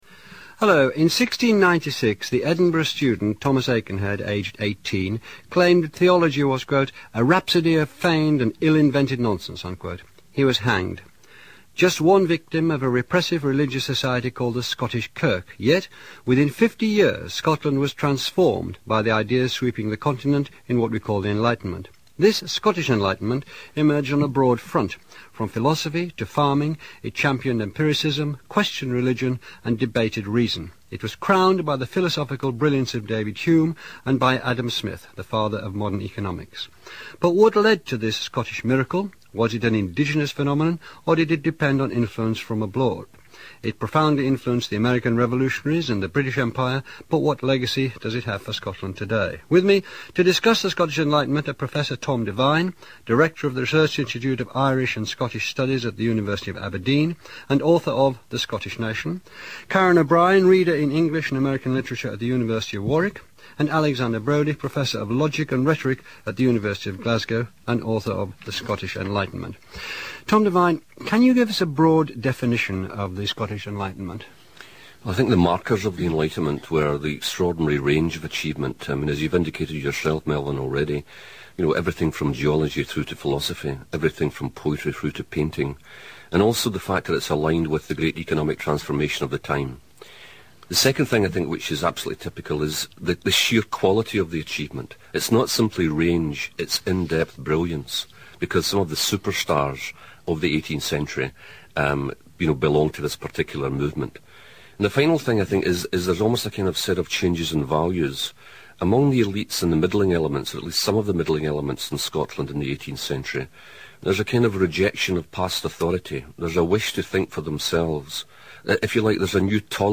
Melvyn Bragg and guests discuss the Scottish Enlightenment of the 18th century.